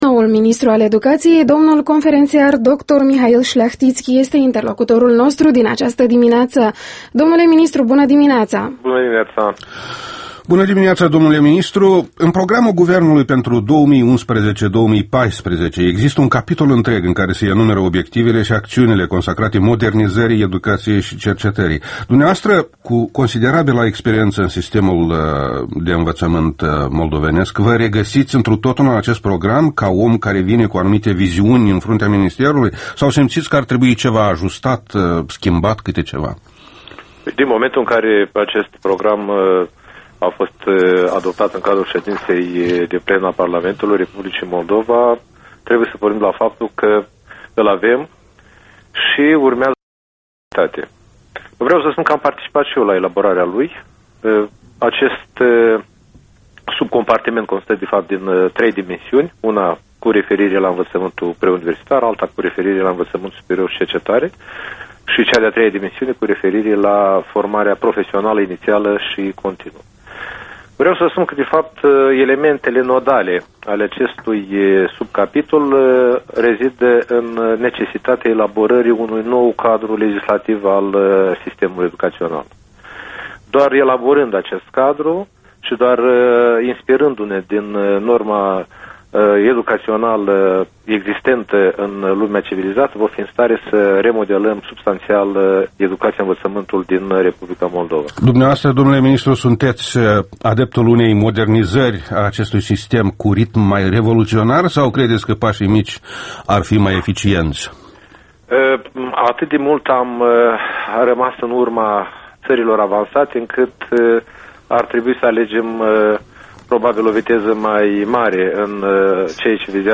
Interviul matinal EL: cu Mihail Șleahtițchi